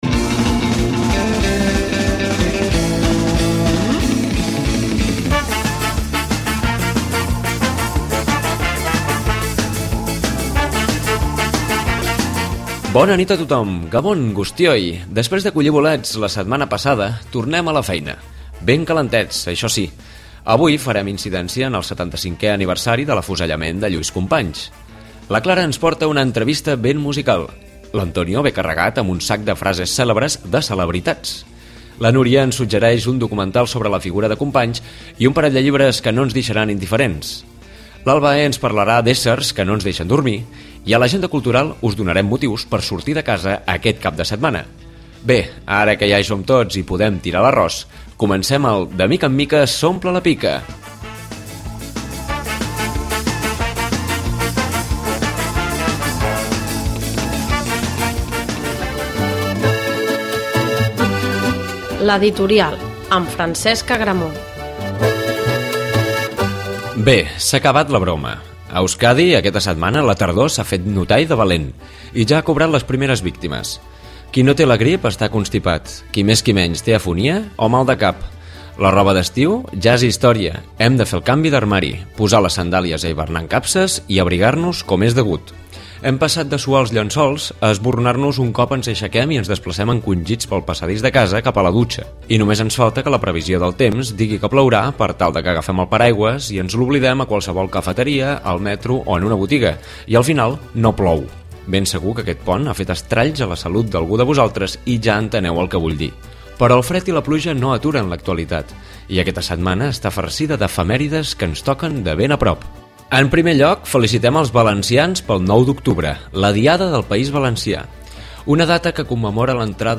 A L’editorial repassem -entre d’altres efemèrides- el 75è aniversari de l’afusellament del President Lluís Companys. A l’entrevista, conversem amb el grup català Txarango.